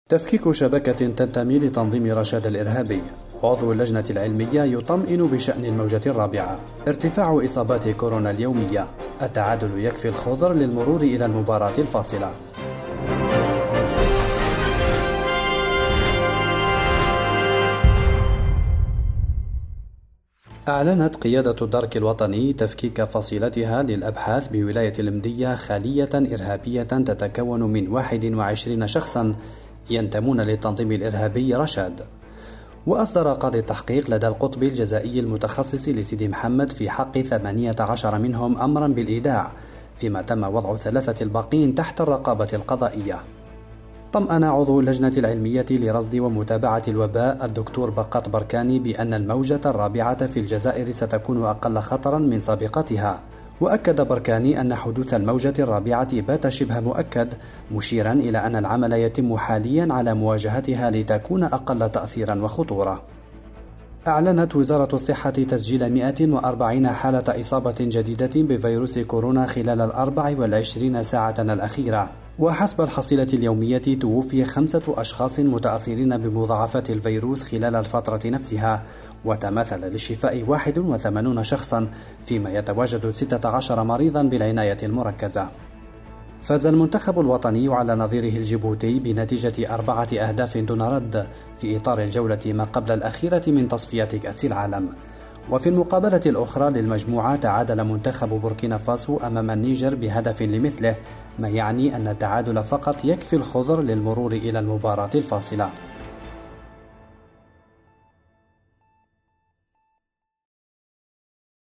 النشرة اليومية: تفكيك شبكة تنتمي لتنظيم “رشاد” الإرهابي – أوراس